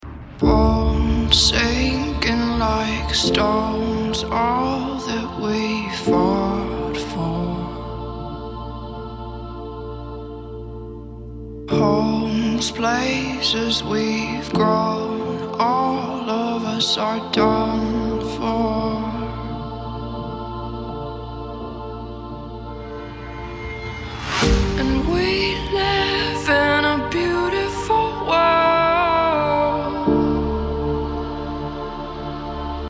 • Качество: 320, Stereo
женский вокал
грустные
спокойные
пианино
красивый женский голос